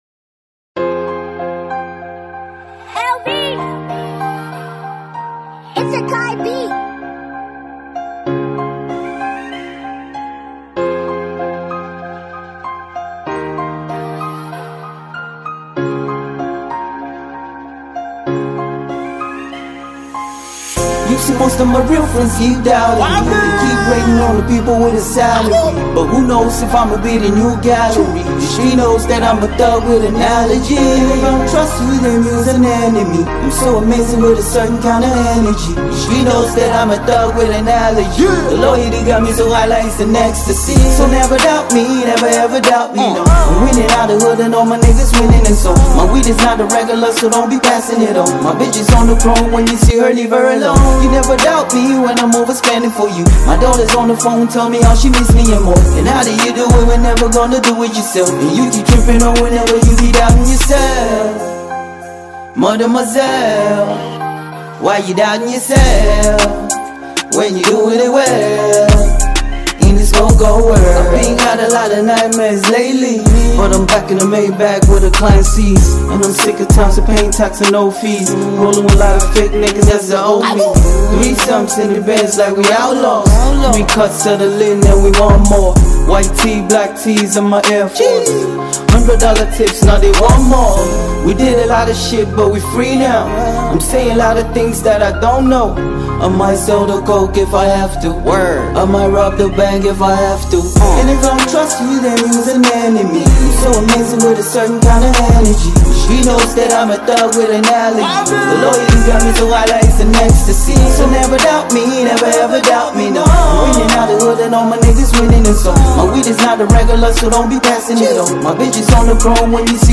Ghanaian hiphop artist